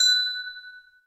noteblock_chime.wav